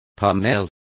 Also find out how pomos is pronounced correctly.